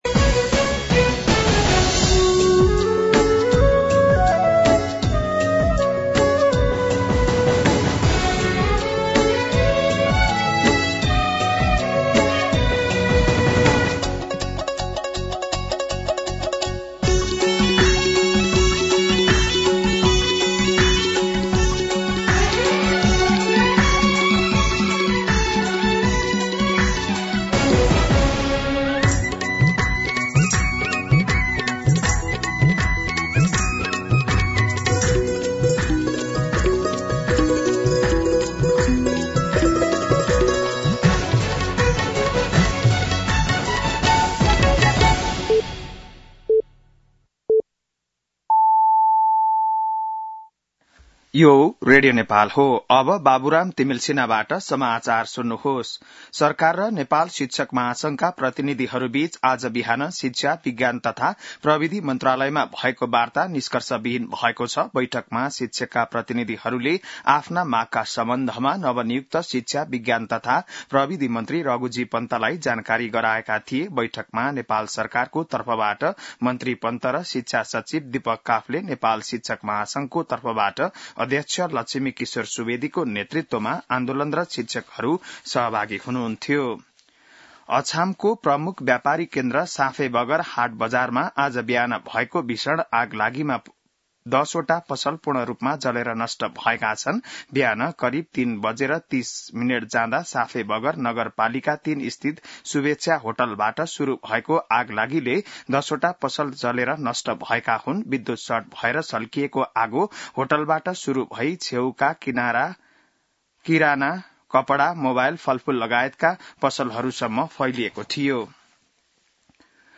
बिहान ११ बजेको नेपाली समाचार : १३ वैशाख , २०८२